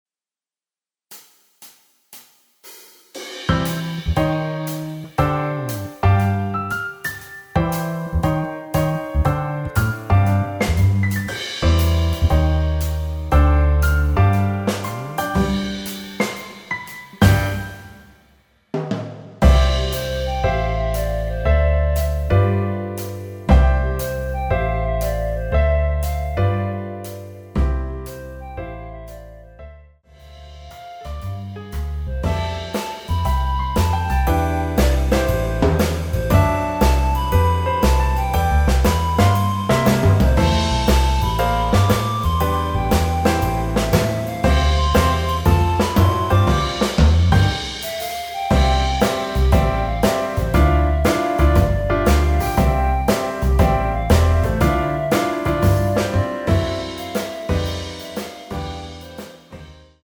원키에서(+5)올린 멜로디 포함된 MR입니다.
앞부분30초, 뒷부분30초씩 편집해서 올려 드리고 있습니다.
중간에 음이 끈어지고 다시 나오는 이유는